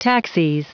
Prononciation du mot taxis en anglais (fichier audio)
Prononciation du mot : taxis
taxis.wav